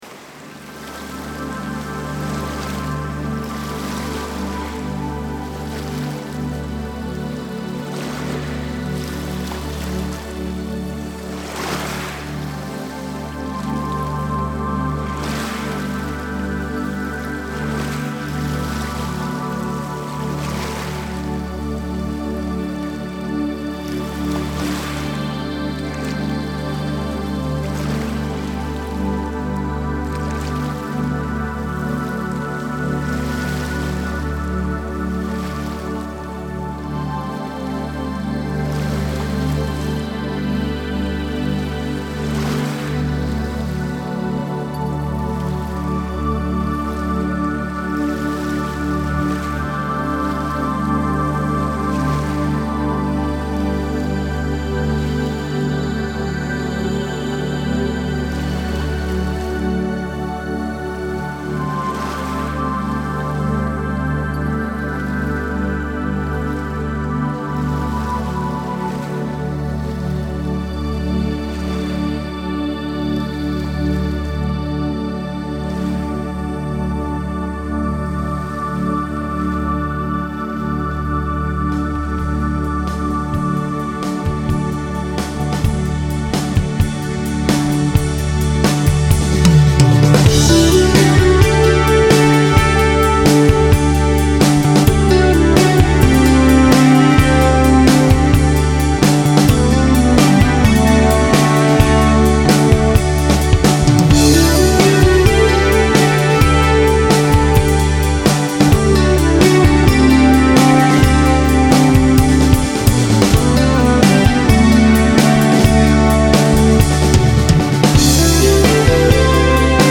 prog. rock